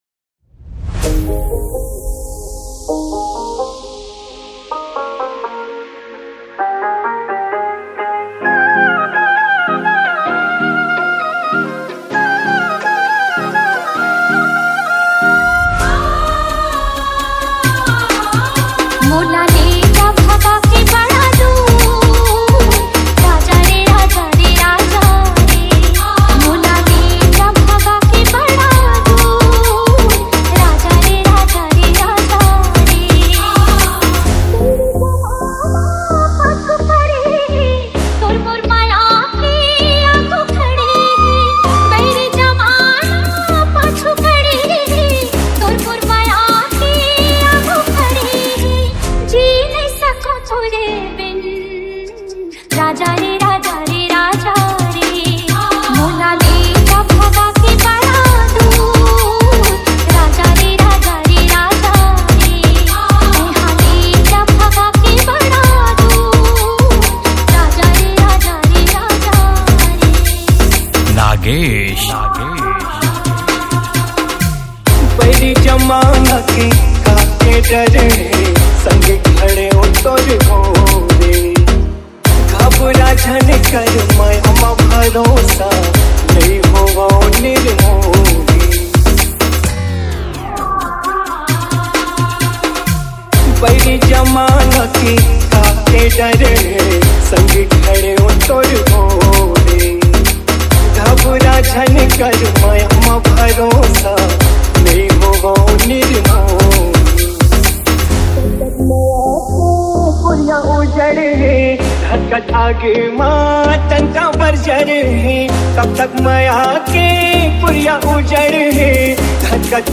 CG LOVE DJ REMIX